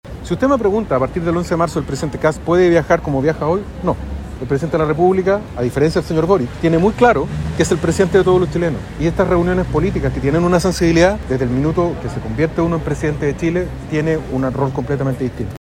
Por su parte, el senador electo Rodolfo Carter defendió la participación de José Antonio Kast en distintos tipos de convocatorias, señalando que estas reuniones con líderes con solo un signo político son pertinentes mientras aún no asuma como Presidente en ejercicio.